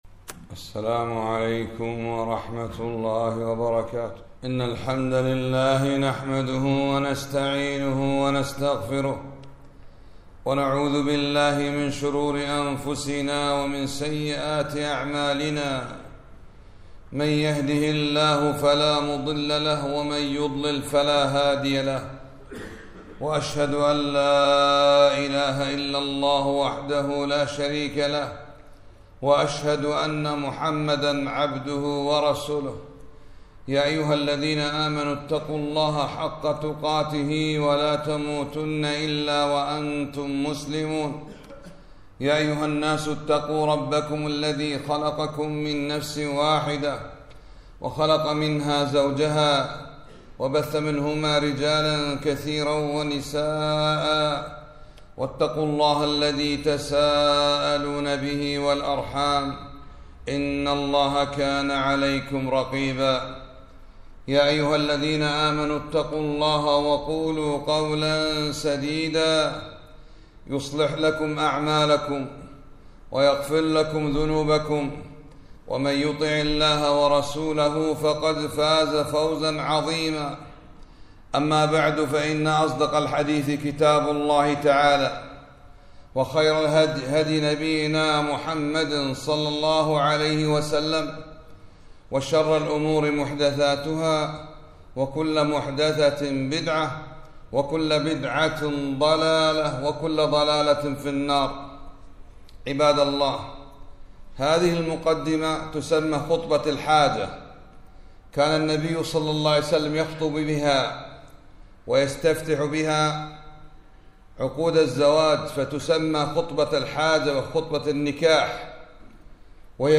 خطبة - بيان ما في (خطبة الحاجة) من معاني عظيمة